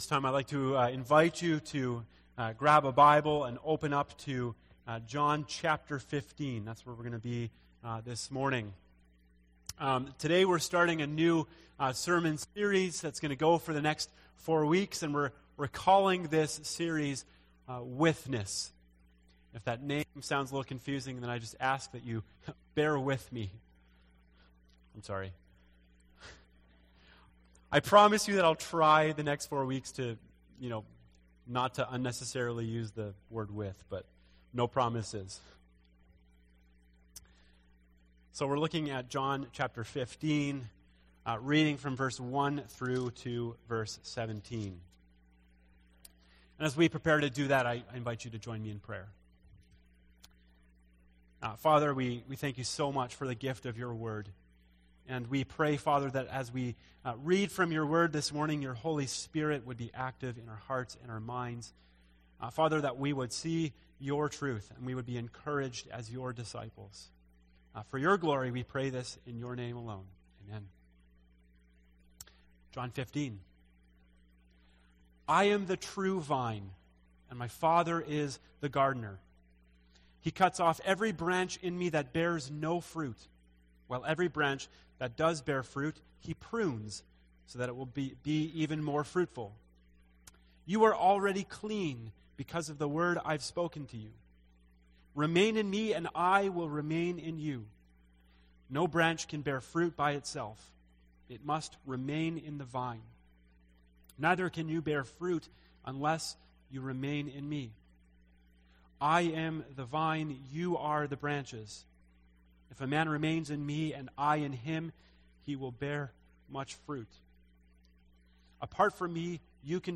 A message from the series "Withness."